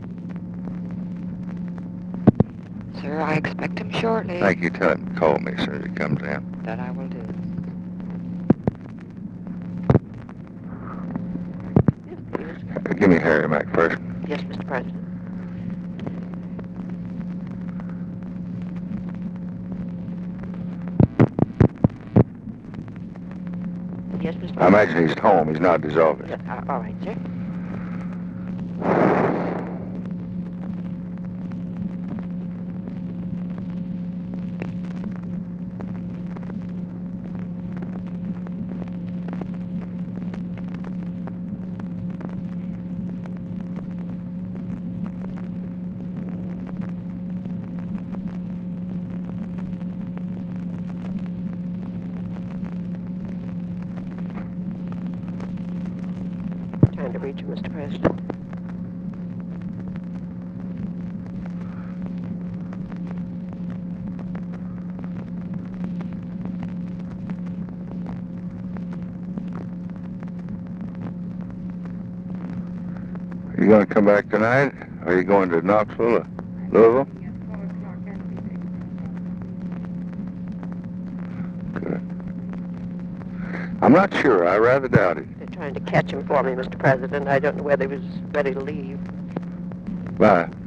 Conversation with TELEPHONE OPERATOR, OFFICE SECRETARY and OFFICE CONVERSATION, November 22, 1968
Secret White House Tapes